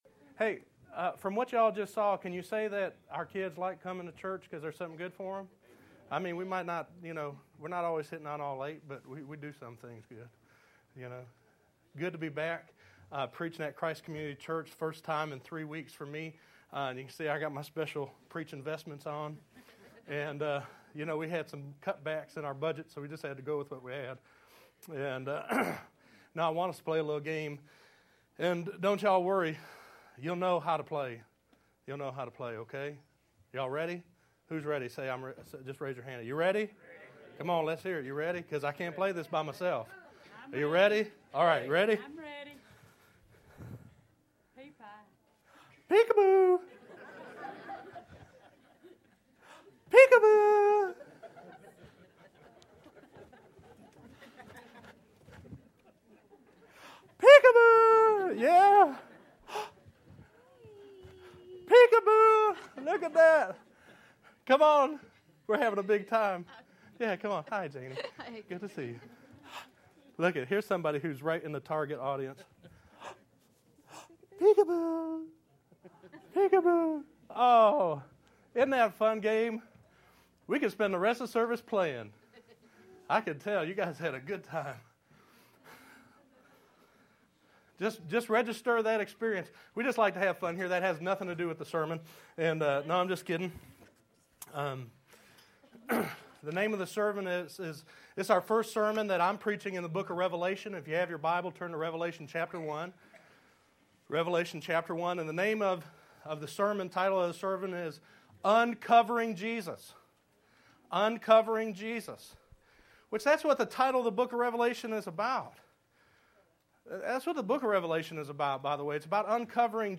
7_22_12_Sermon.mp3